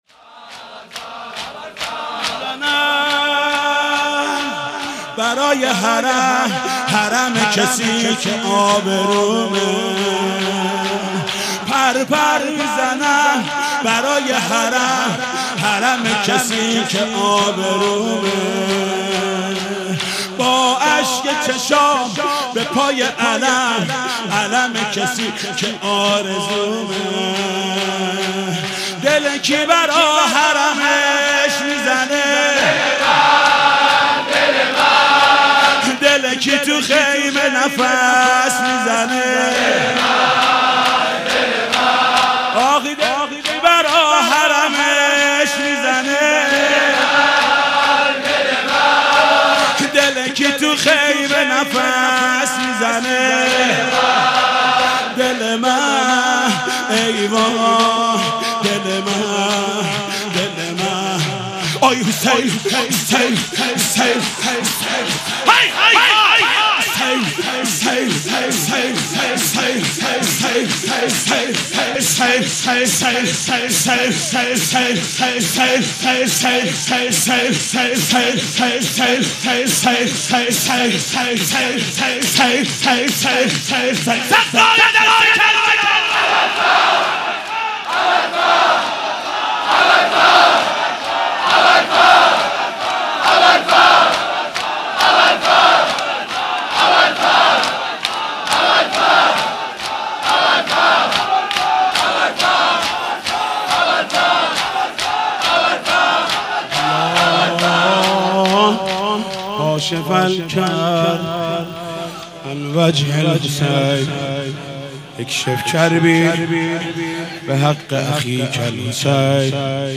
حاج محمود کریمی (شب سوم محرم۱۳۸۷)